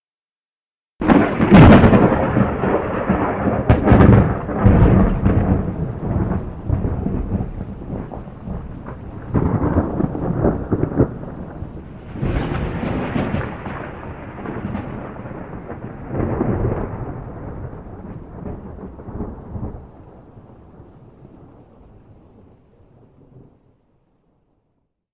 lightning.mp3